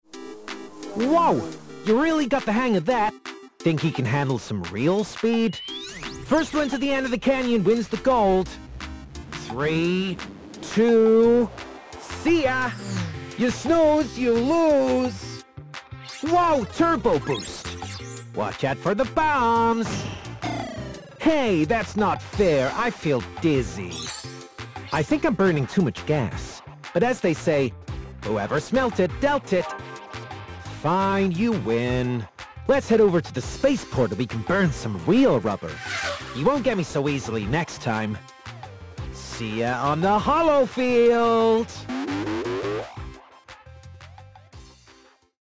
20/30's Welsh, Expressive/Warm/Natural
Comedy Racer (US) Victorian Gentleman (RP) Troll (London) Robot/Computer